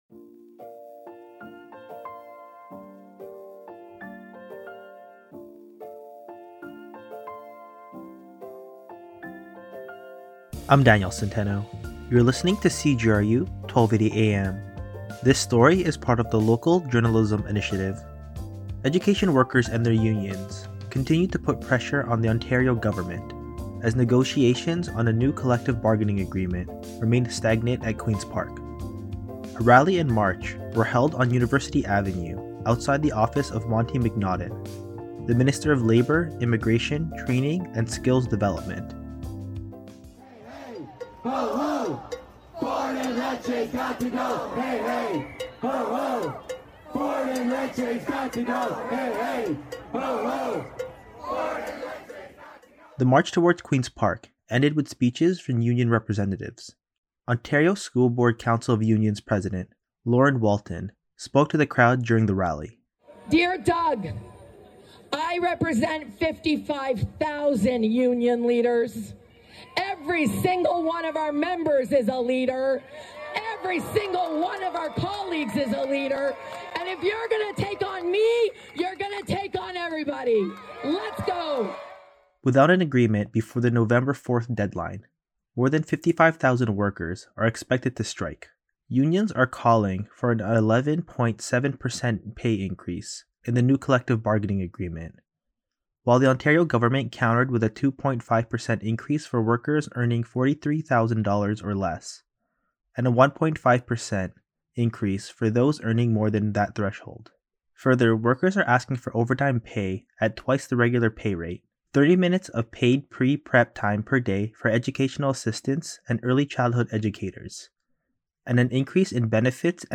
The march towards Queen’s Park ended with speeches from union representatives, including the Canadian Union of Public Employees, also known as CUPE Ontario.